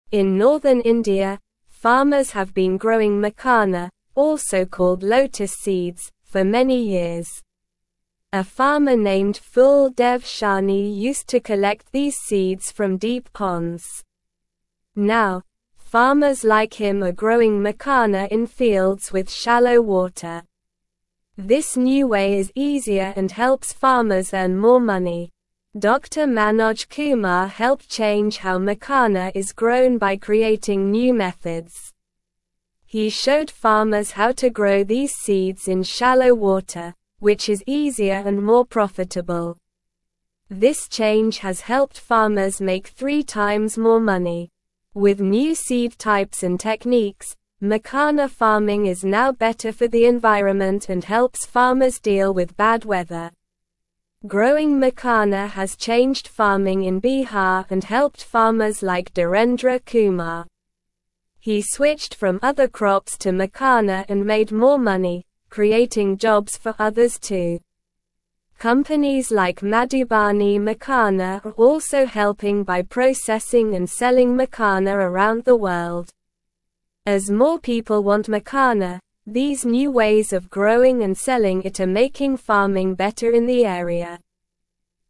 Slow
English-Newsroom-Lower-Intermediate-SLOW-Reading-Growing-Makhana-Seeds-Helps-Farmers-Earn-More-Money.mp3